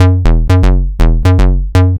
TSNRG2 Bassline 006.wav